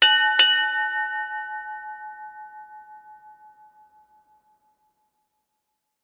Church Bells, Distant, B
bell Bell bells Bells church Church church-bell ding sound effect free sound royalty free Sound Effects